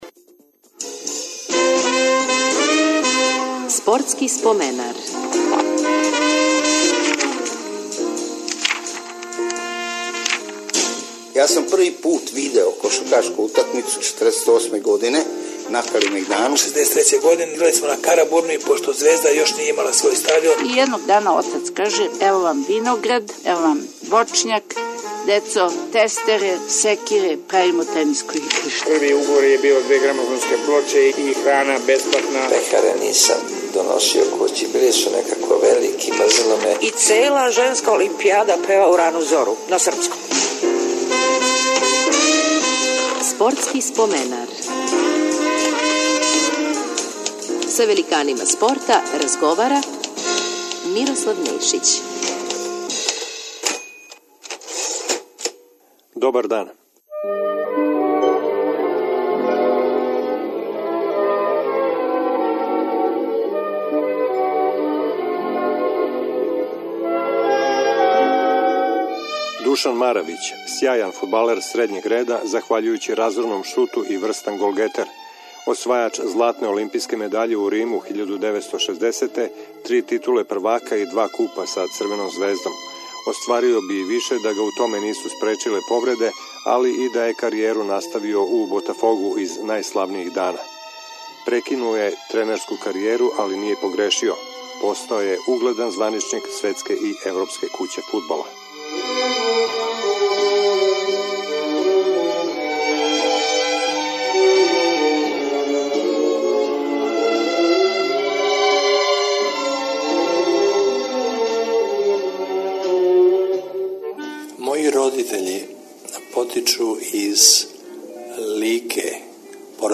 Гост нам је фудбалер Душан Маравић. Каријеру је почео у Радничком из Бајмока, наставио у суботичком Спартаку, а врхунске домете остварио током шест сезона играња у Црвеној звезди.